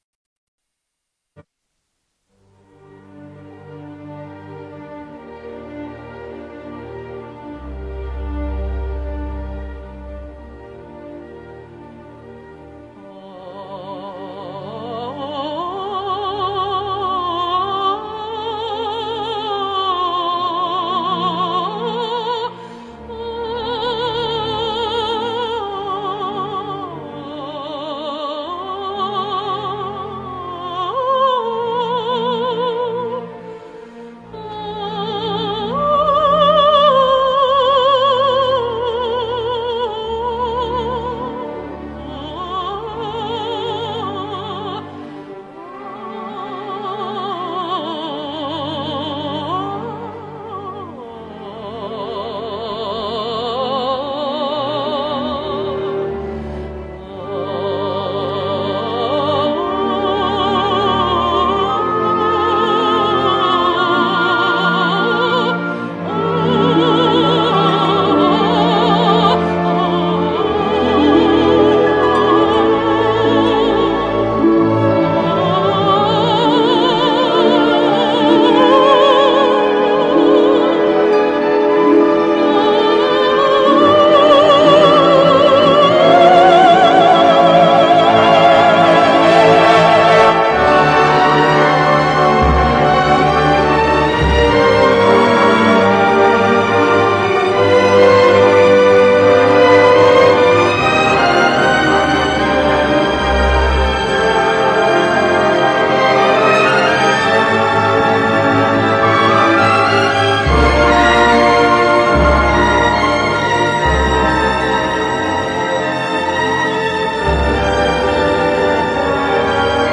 для меццо-сопрано та симфонічного оркестру.